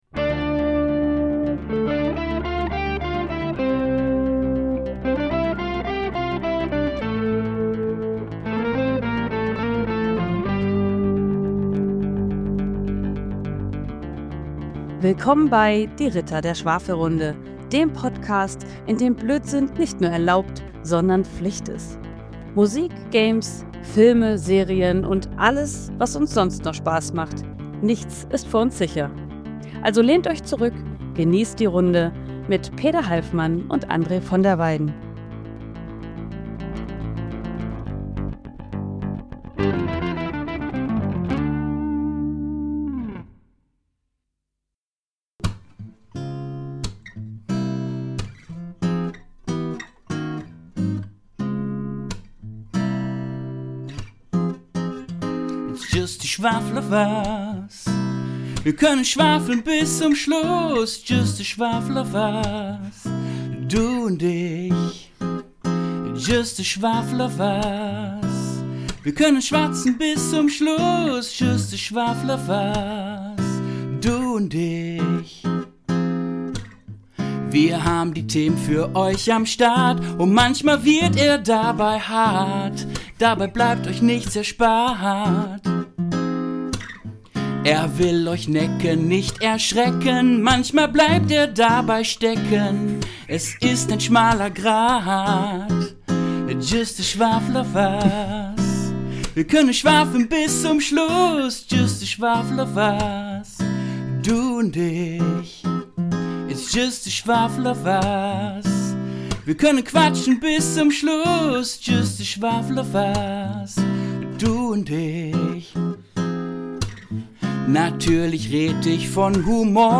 All das in einer knackigen Dreiviertelfolge – aufgenommen unter abenteuerlichen Internetzbedingungen.